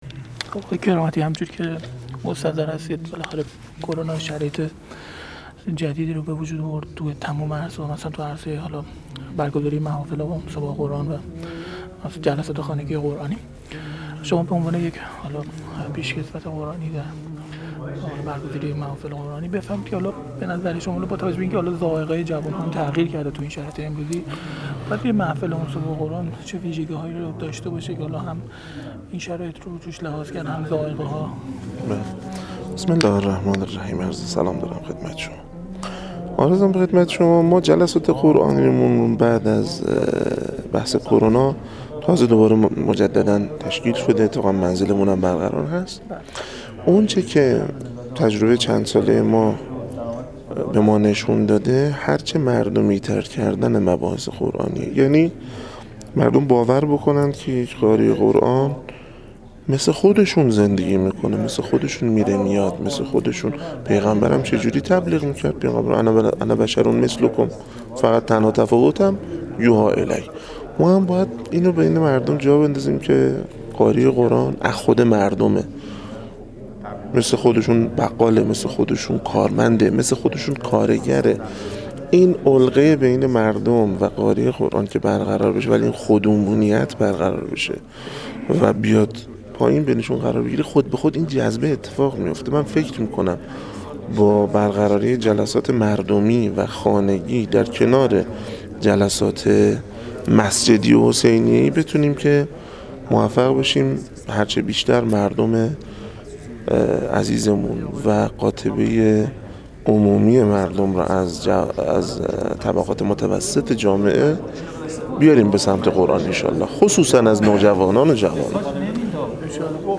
در گفت‌وگو با ایکنا